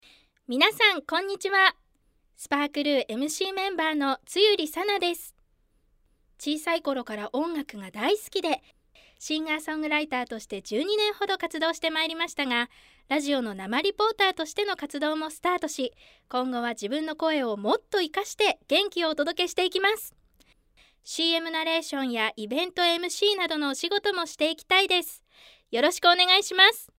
自己紹介